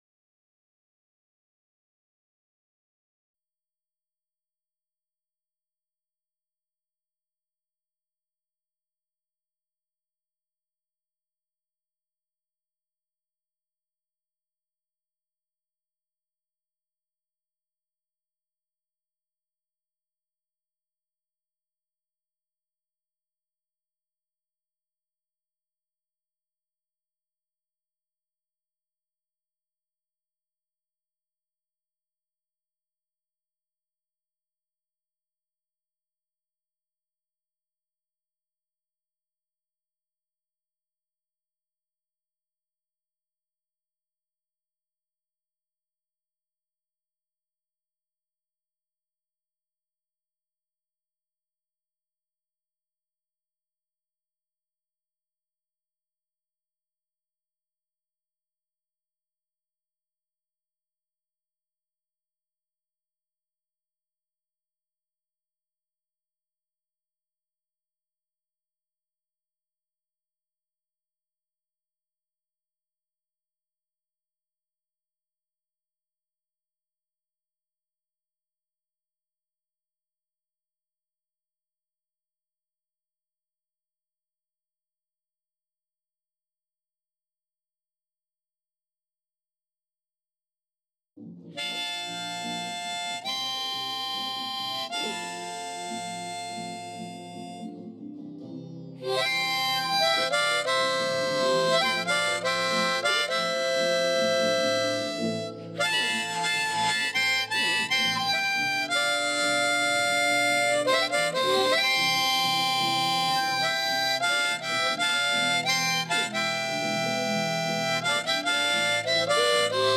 harmonica.wav